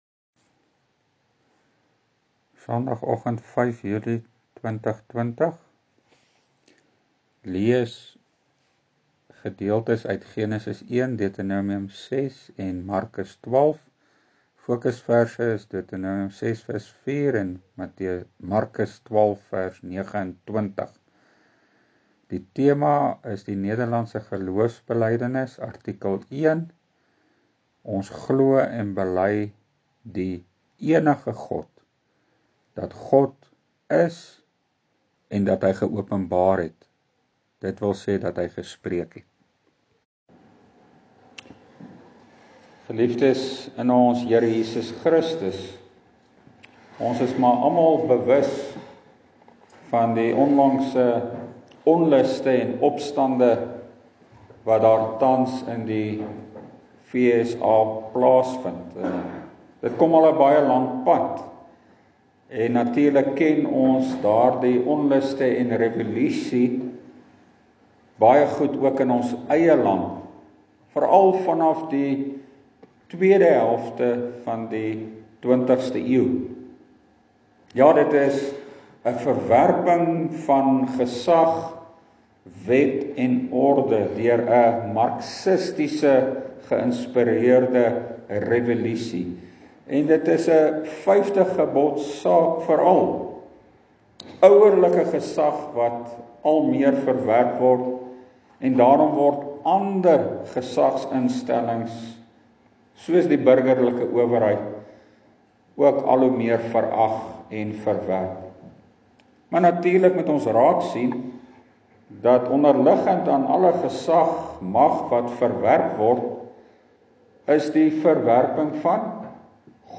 Leerprediking: Nederlandse Geloofsbelydenis artikel 1, deel 1 – Die enige God (Deut. 6:4 & Mark. 12:29)